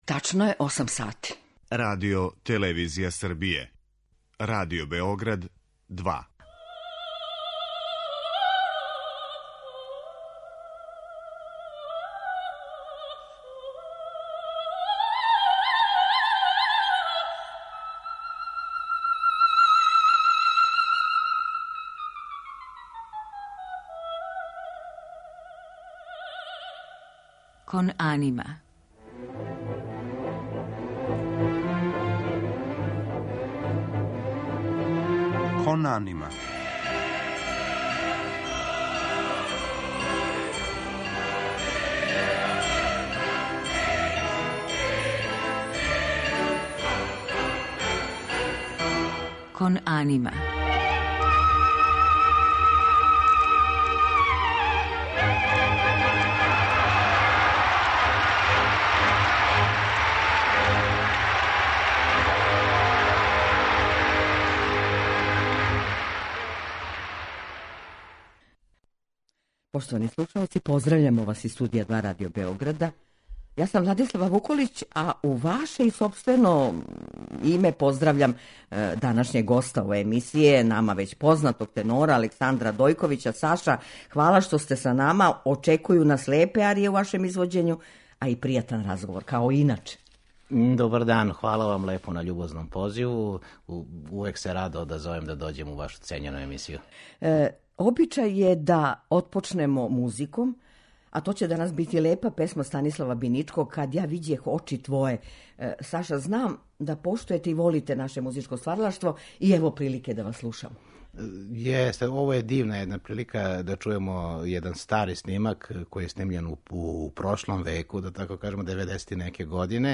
У музичком делу биће емитоване арије и сцене из споменутих опера.